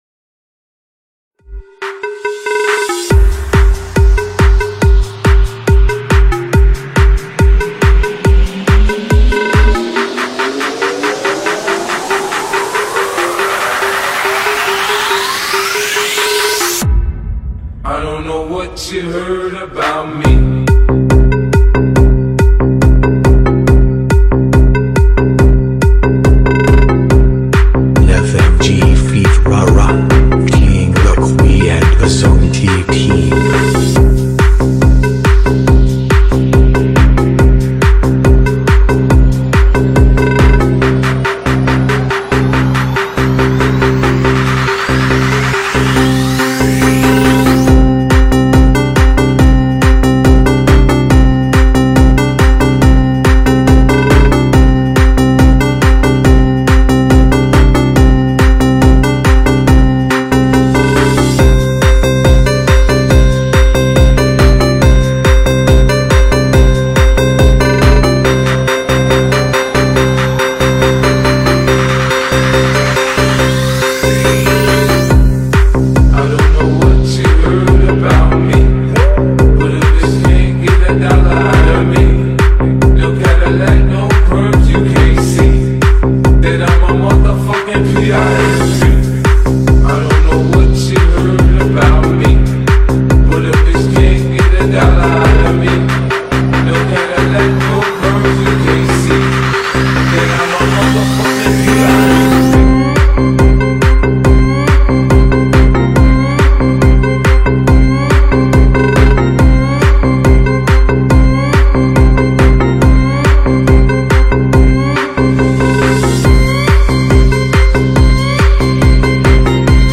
本铃声大小为3104.4KB，总时长329秒，属于DJ分类。